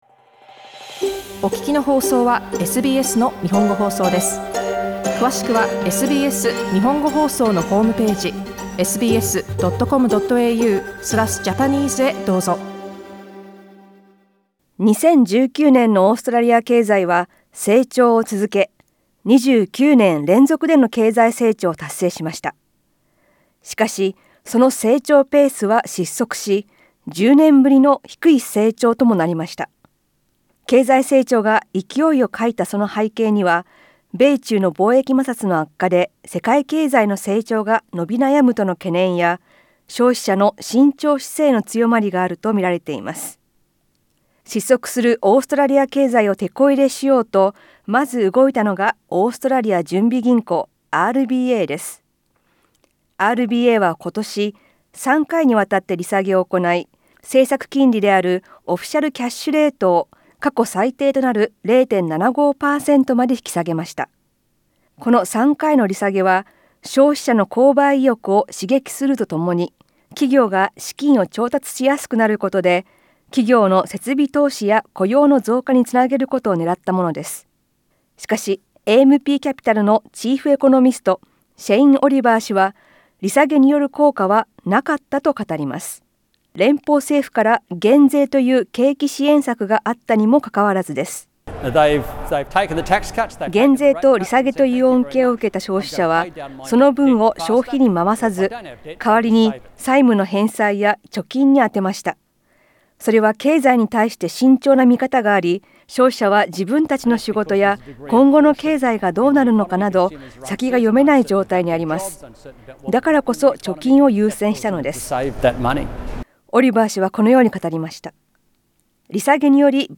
＊詳しい音声リポートは写真をクリックしてどうぞ READ MORE 2019年、今年のオーストラリア・ビジネス業界 オーストラリアや世界の話題を、日本語で！